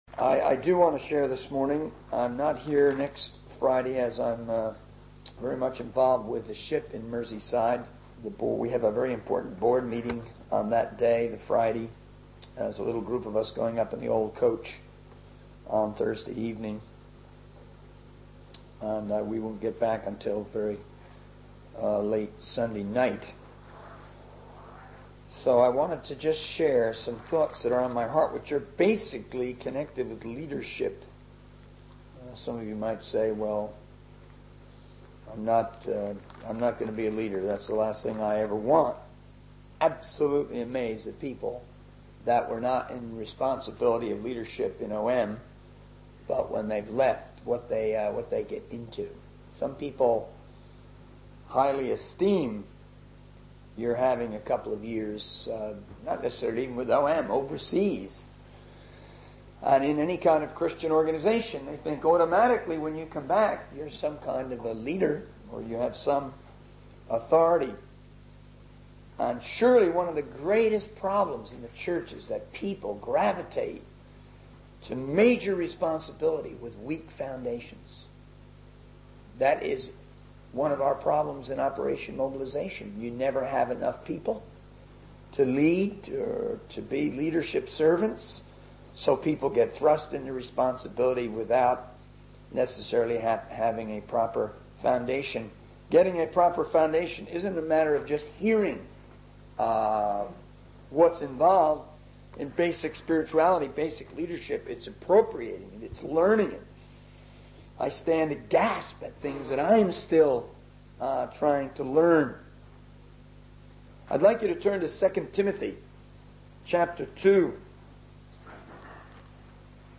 In this sermon, the speaker emphasizes the importance of personal relationships and bonding in the journey of faith.